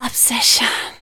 WHISPER 03.wav